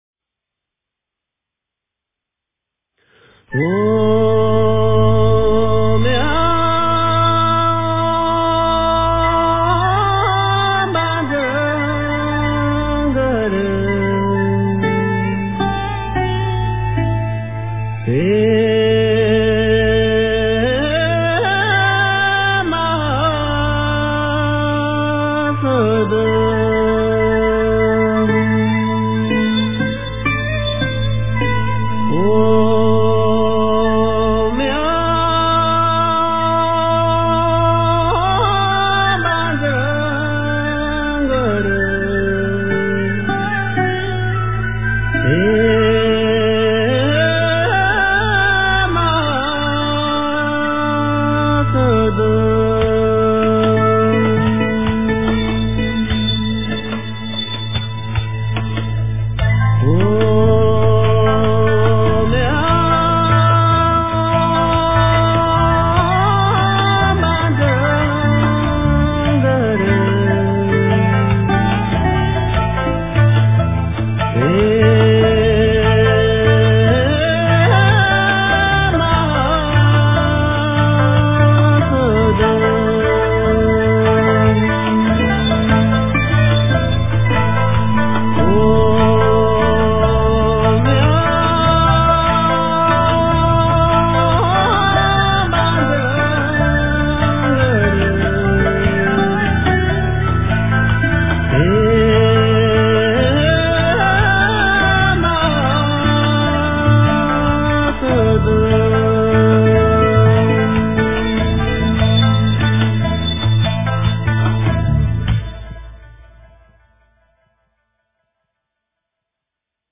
真言
佛教音乐